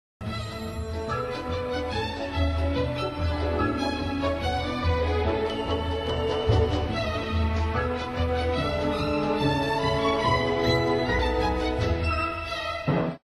Heiteres Konzertstück